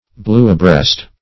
Search Result for " bluebreast" : The Collaborative International Dictionary of English v.0.48: Bluebreast \Blue"breast`\, n. (Zool.) A small European bird; the blue-throated warbler.